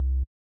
INT Bass C1.wav